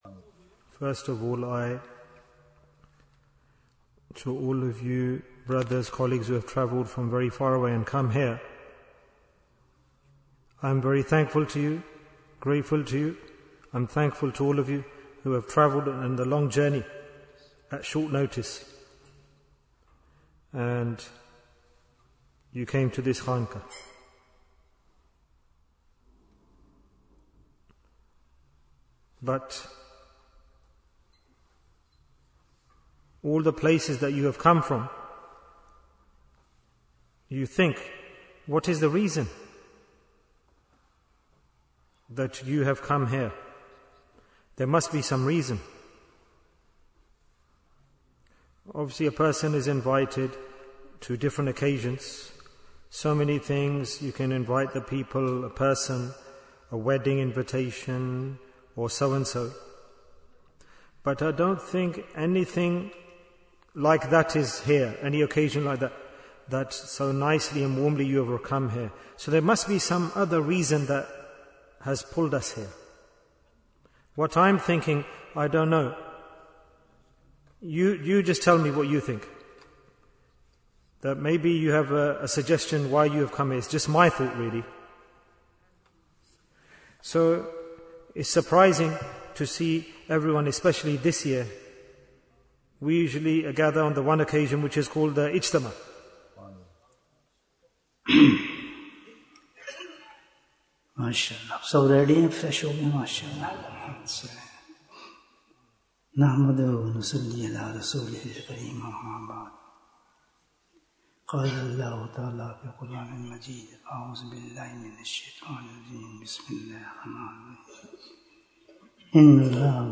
Welcome Ramadhan 2026 Bayan, 180 minutes14th February, 2026